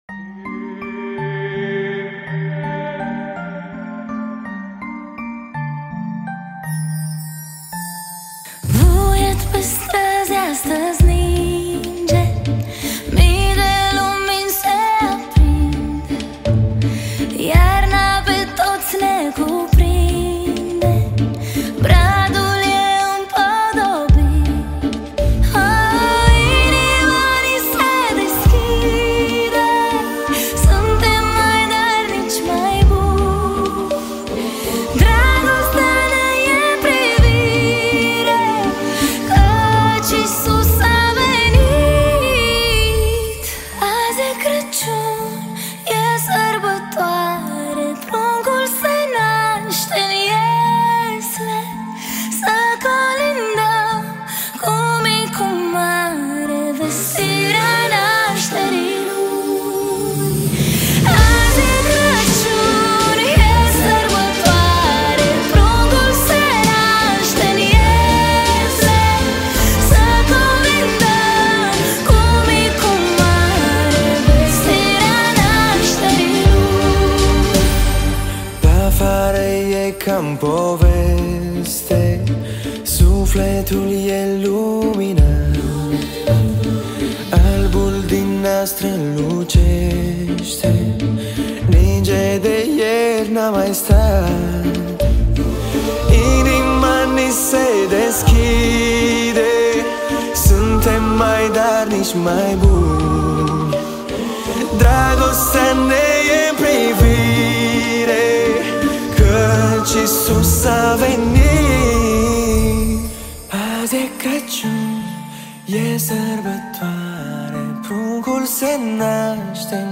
Data: 11.10.2024  Colinde Craciun Hits: 0